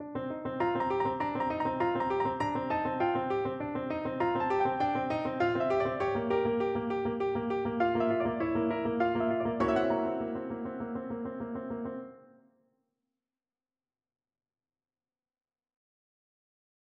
클로드 드뷔시는 ''어린이 놀이방'' 모음곡 중 "눈이 춤을 춘다"에서 B플랫의 반복되는 음 위에 삼중음표 분할 교차 리듬으로 만들어진 선율을 도입하여, 아래에 있는 두 개의 춤추는 눈송이 선을 구성하는 16분음표와는 독립적으로 이 계층을 상쇄시킨다.[6] 이 부분에서는 페달, 오스티나토, 선율의 폴리리듬 중첩 때문에 리듬의 정확성에 각별한 주의가 필요하다.[7]